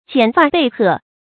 剪發被褐 注音： ㄐㄧㄢˇ ㄈㄚˋ ㄆㄧ ㄏㄜˋ 讀音讀法： 意思解釋： 見「剪發披緇」。